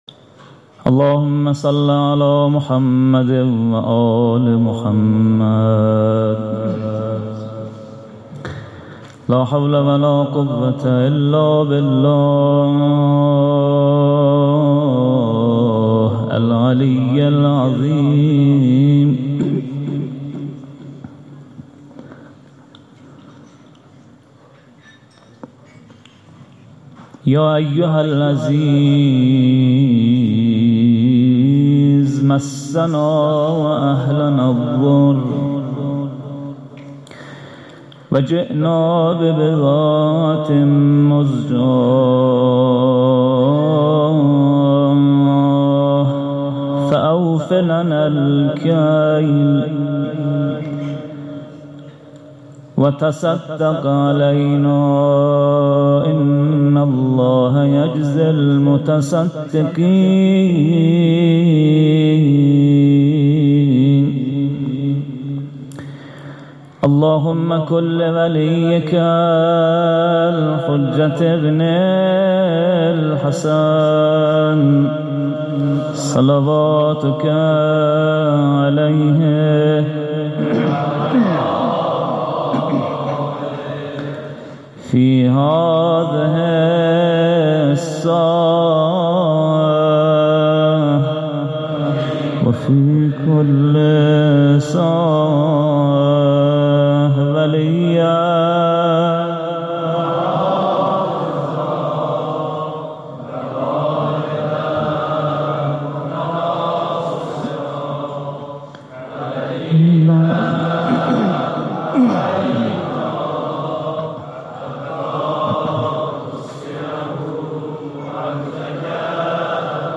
هیأت محبان اهل بیت علیهم السلام چایپاره
محرم ۹۵. شب اول ( مقدمه و روضه)